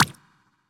flip2.ogg